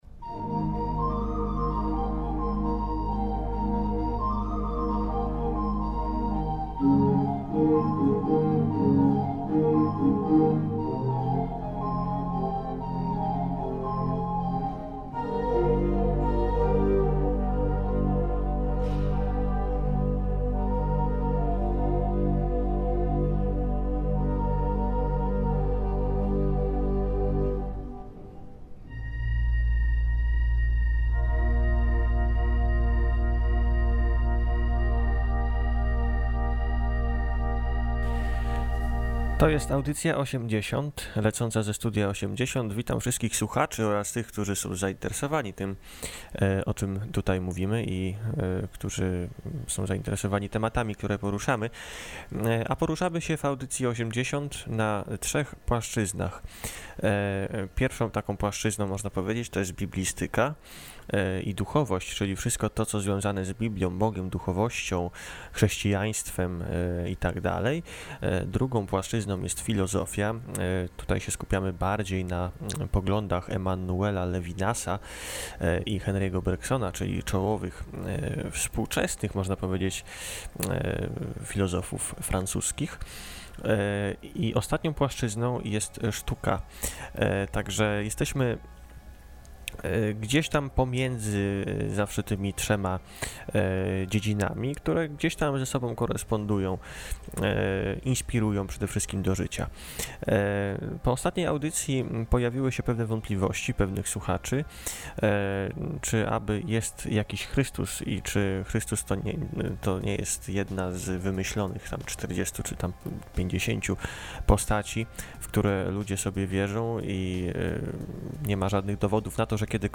Każda audycja obfituje w masę niekonwencjonalnej muzyki, granej przez wyjątkowych, aczkolwiek bardzo często niszowych artystów.